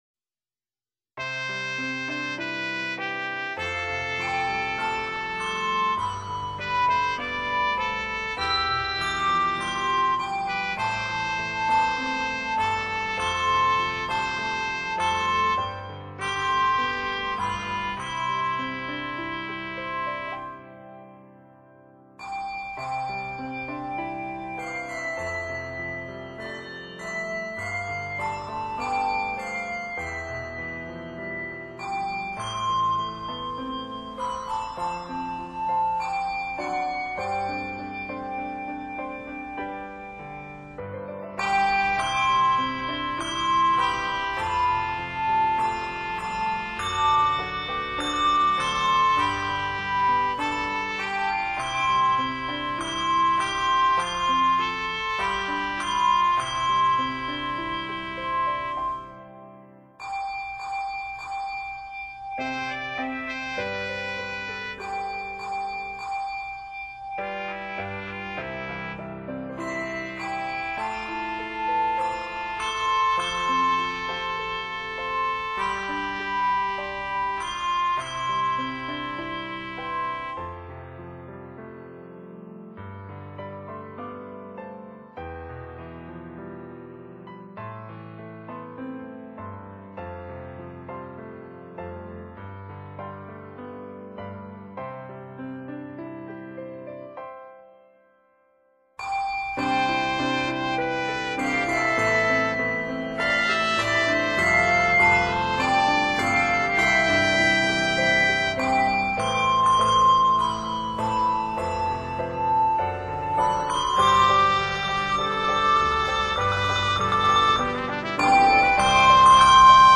Arranged in C Major, this piece is 74 measures.